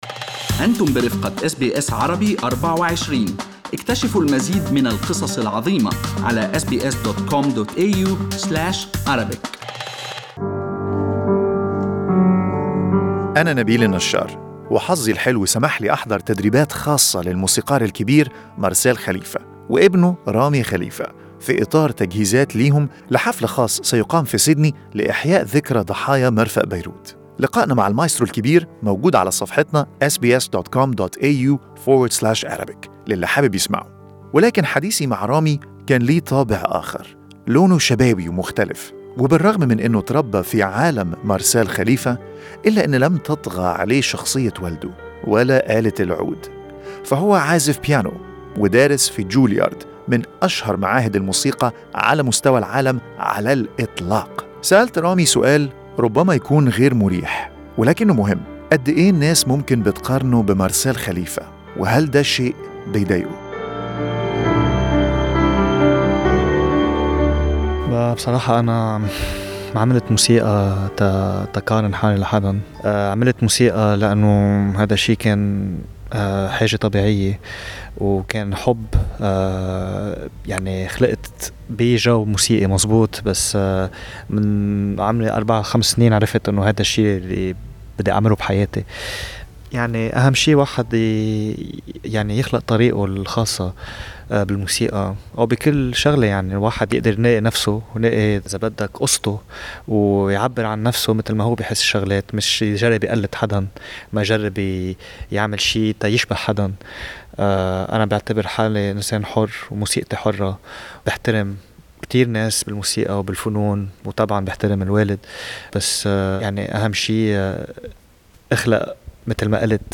لقاء خاص